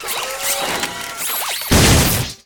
robot punch.ogg